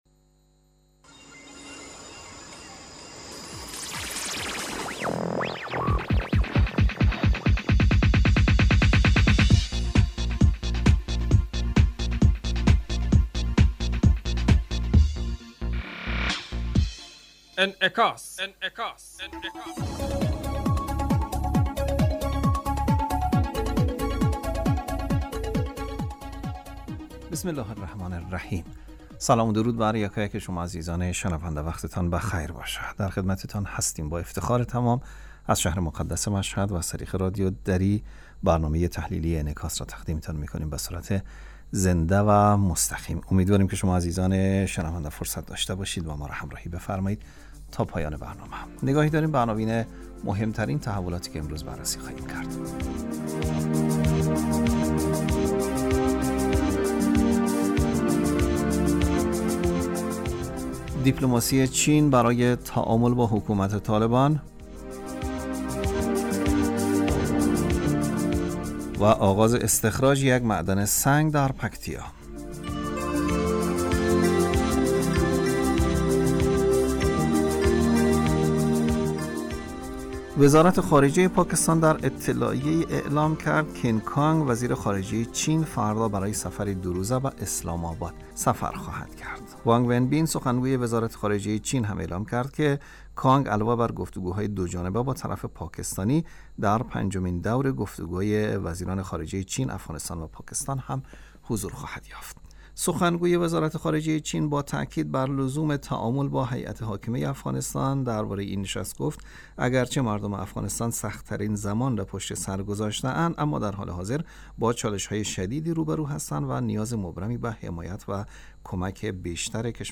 برنامه انعکاس به مدت 30 دقیقه هر روز در ساعت 05:40 بعد از ظهر بصورت زنده پخش می شود. این برنامه به انعکاس رویدادهای سیاسی، فرهنگی، اقتصادی و اجتماعی مربوط به افغانستان و تحلیل این رویدادها می پردازد.